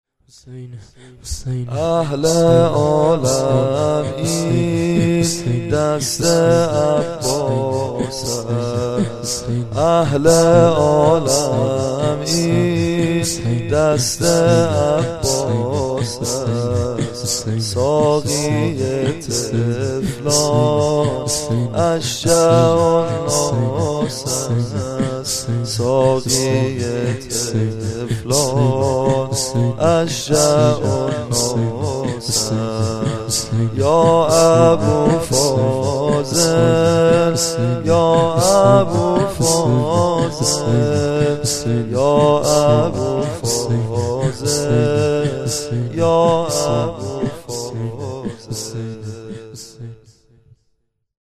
در استودیوی عقیق